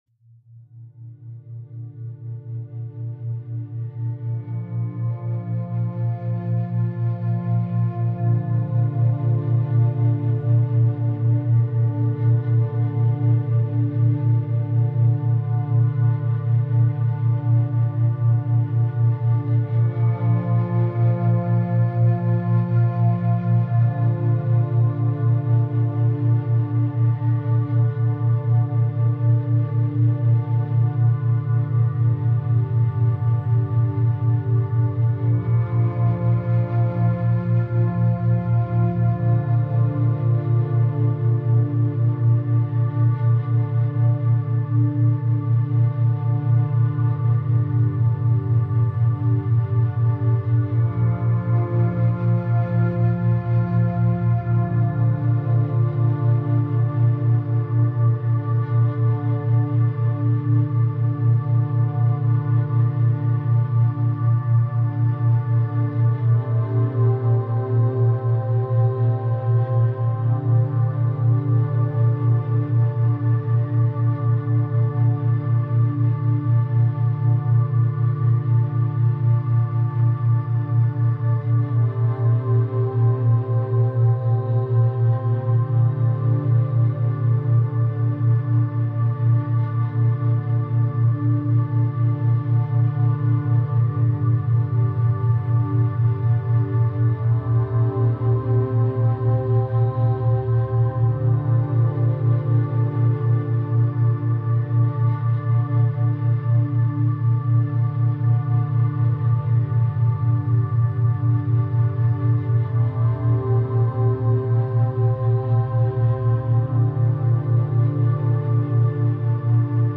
Café Rain Ambience – Gentle Focus for Studying and Reading